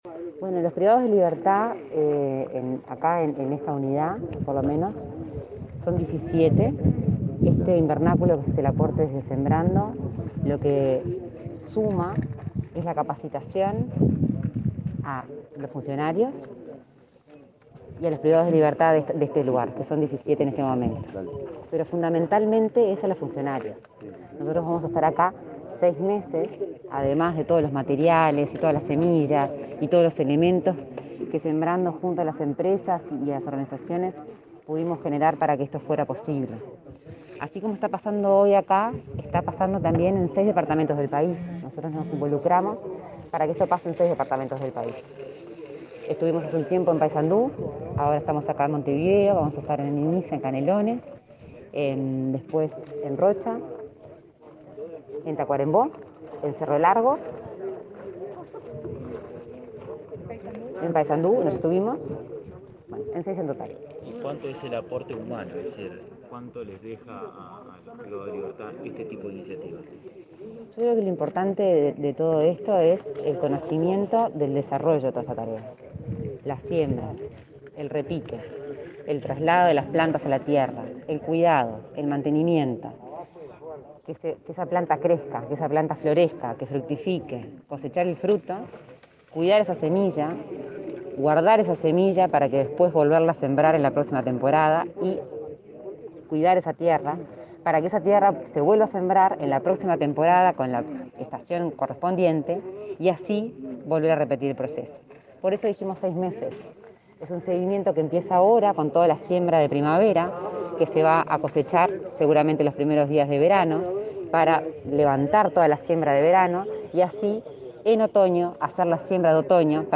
Declaraciones a la prensa de Lorena Ponce de León